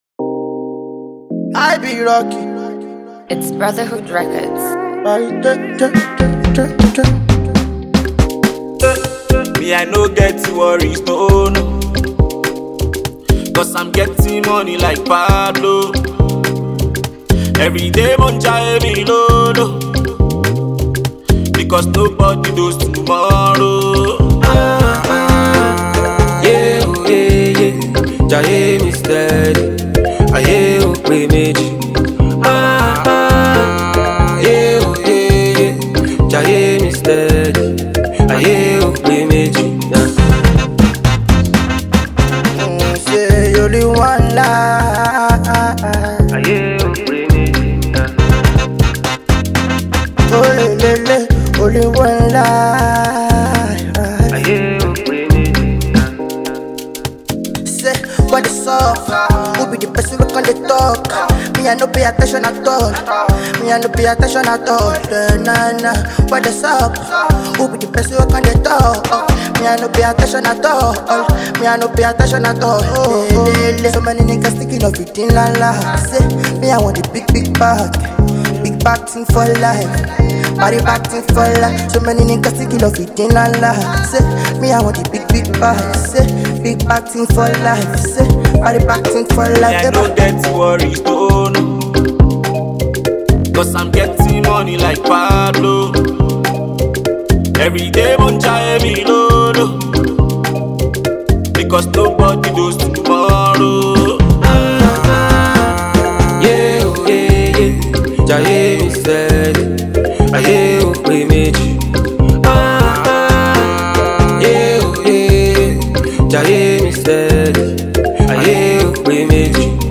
classic motivational song